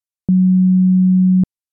To begin load up Sylenth in your host software, load an empty preset and set oscillator one in sine wave. Now try to find the bass key, in this case it’s a F# held for 2 beats.